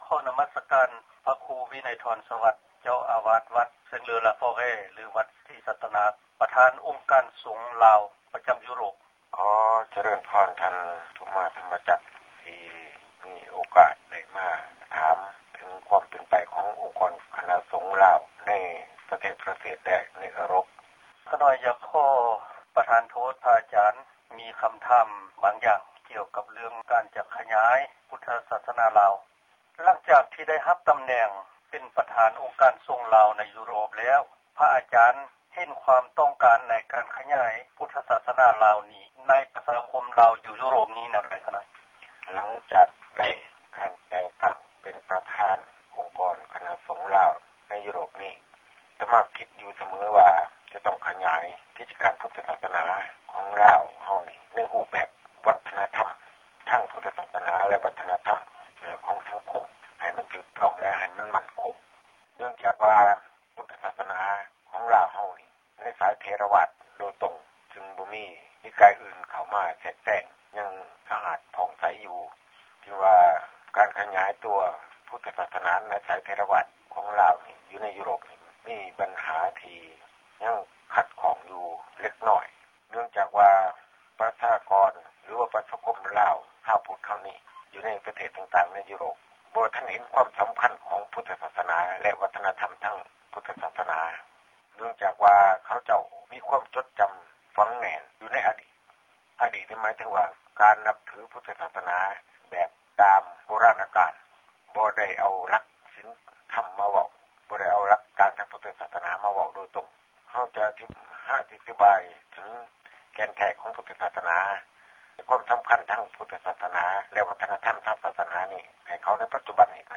ສັມພາດພິເສດ